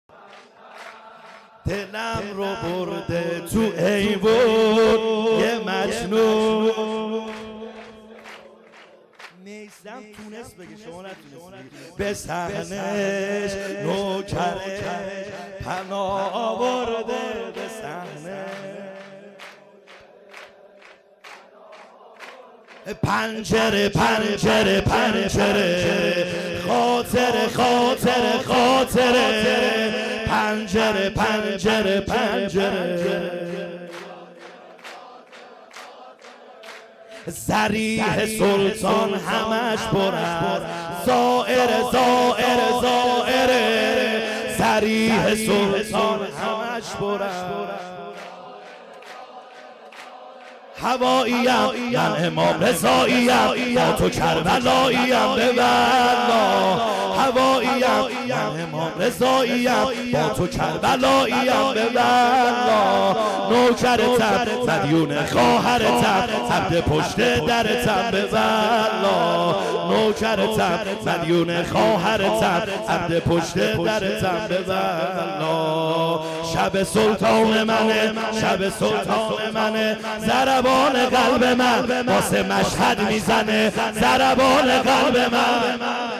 خیمه گاه - بیرق معظم محبین حضرت صاحب الزمان(عج) - سرود | دلم رو برده تو ایوون